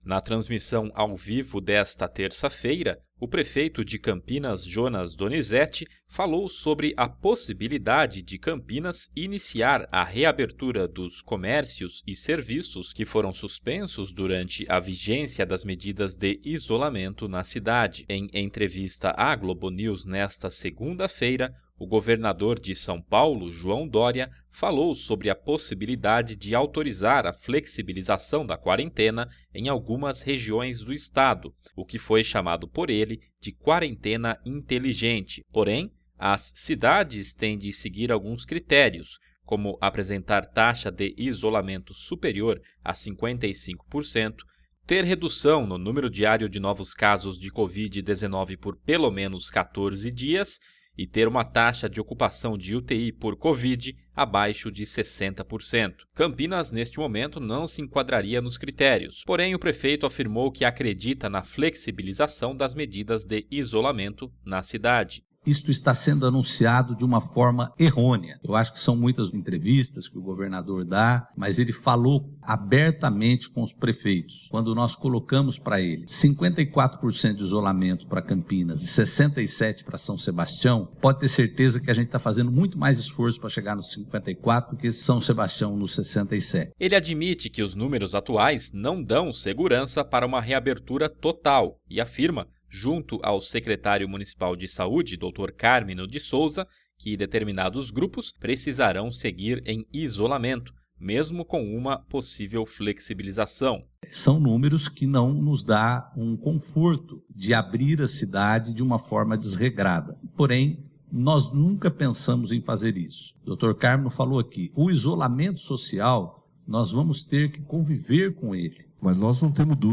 Na transmissão ao vivo desta terça-feira (26), o Prefeito de Campinas, Jonas Donizette, falou sobre a possibilidade de Campinas iniciar a reabertura dos comércios e serviços que foram suspensos durante a vigência das medidas de isolamento na cidade.